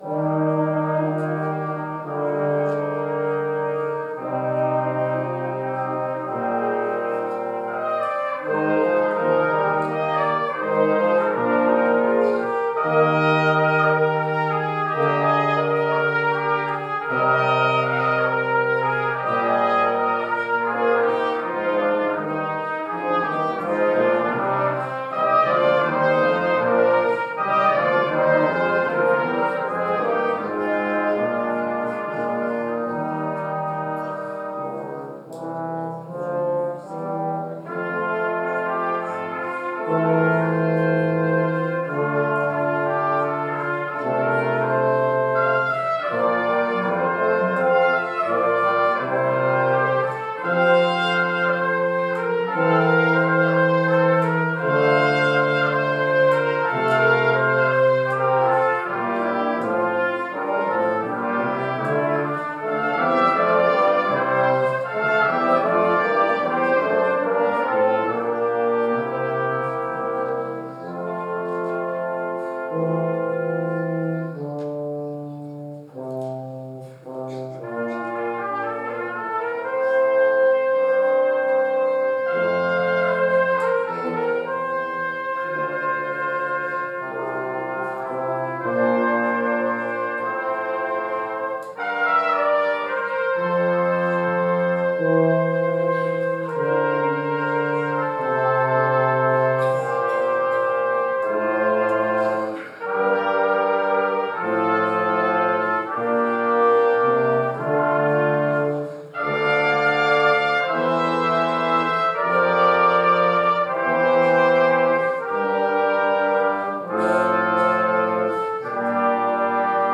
Sunday Morning Music
Brass Ensemble Prelude